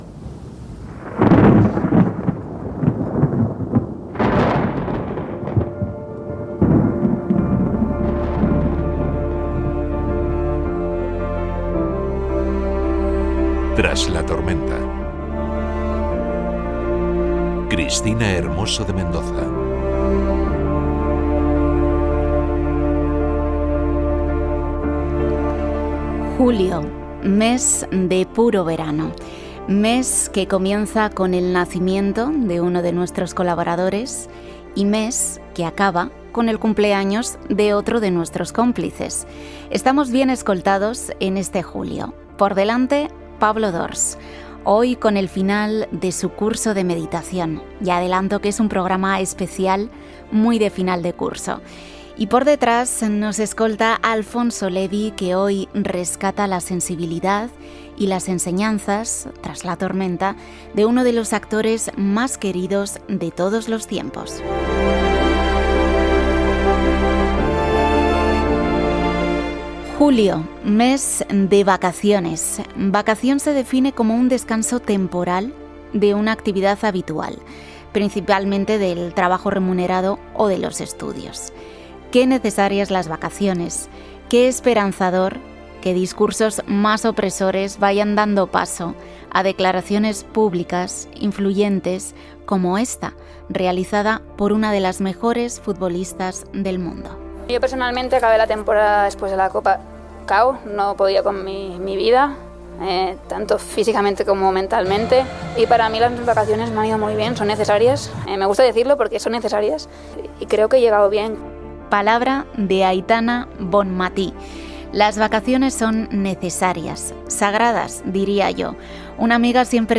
Careta de programa